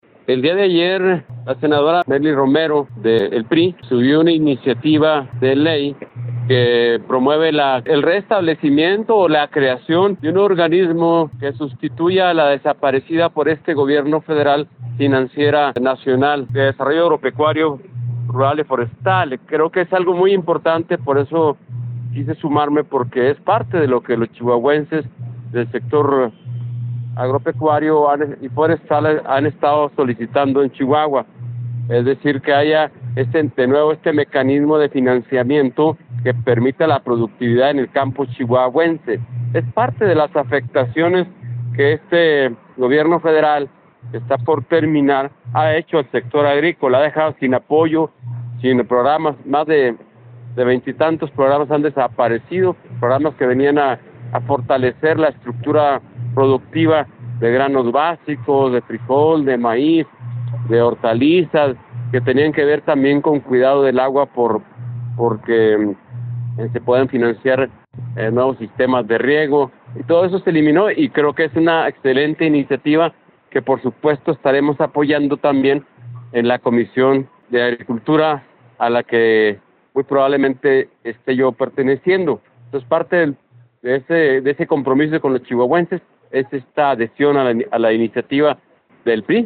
MENSAJE DE MARIO VÁZQUEZ | SENADOR POR CHIHUAHUA